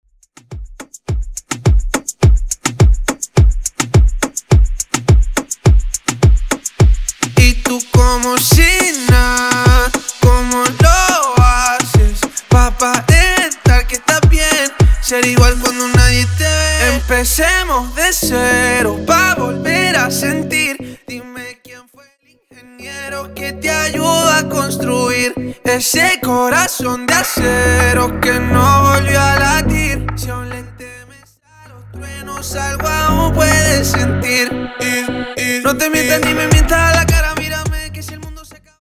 Extended Dirty Intro Acapella